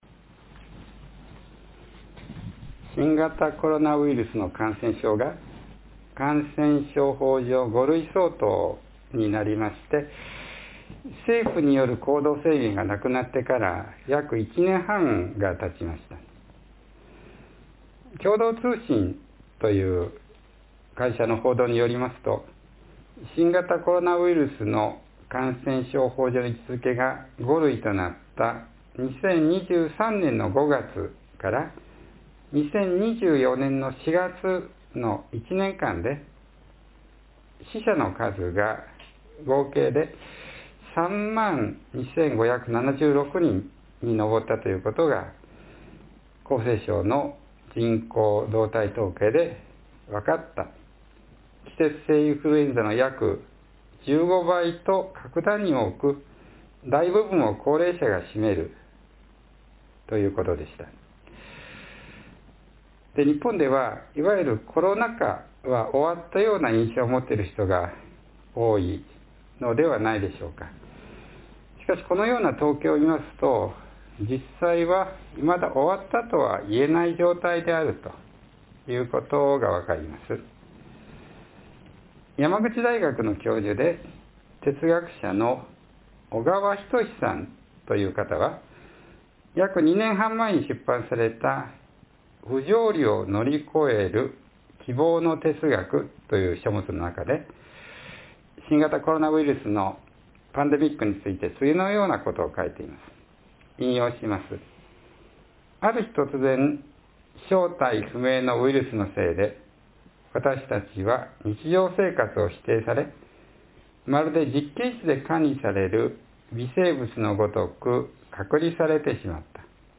（11月17日の説教より） It has been about a year and a half since COVID-19 became equivalent to Category 5 under the Infectious Diseases Control Law, and the Japanese government’s restrictions on behaviour were lifted.